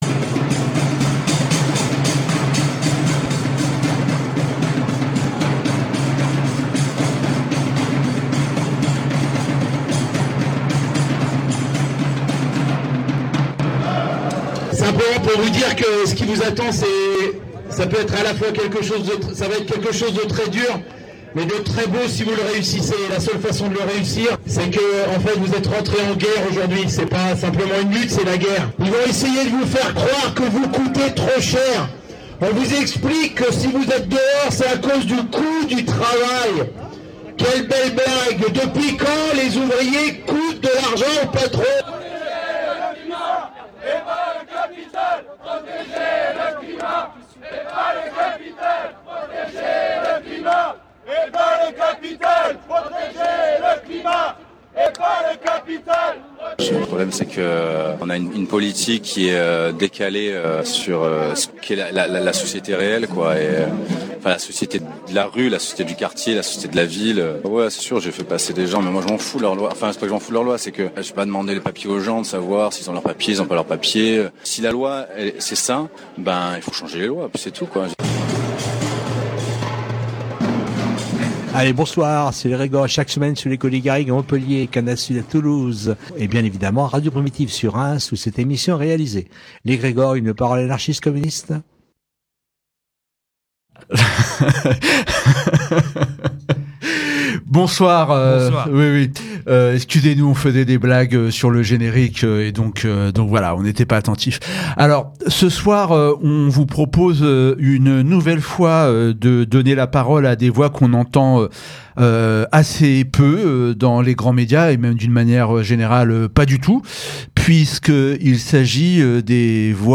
Conversation entre Salah Hamouri et Georges Abdallah - Radio Primitive
Depuis Beyrouth, Salah Hamouri et Georges Ibrahim Abdallah échangent autour de leurs expériences carcérales respectives. Leur conversation est également l’occasion de revenir sur leurs conceptions et leurs analyses du mouvement national palestinien et sur la situation actuelle.